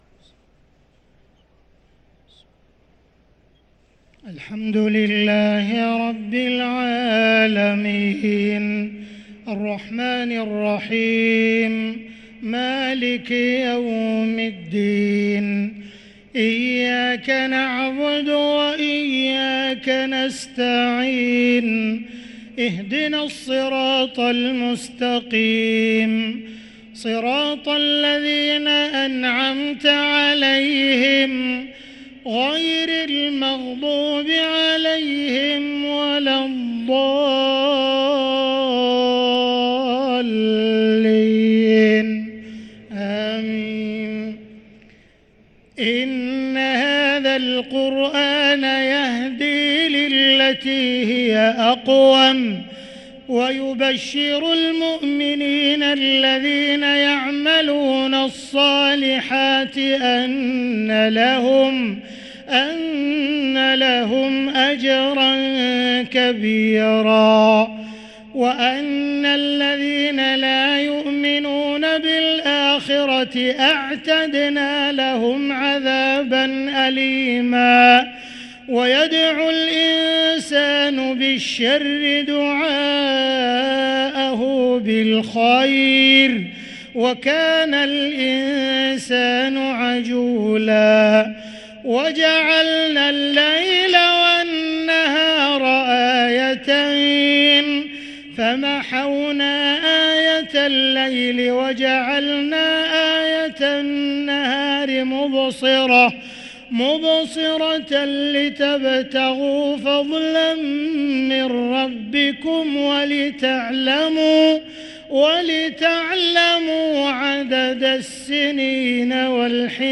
صلاة العشاء للقارئ عبدالرحمن السديس 14 رمضان 1444 هـ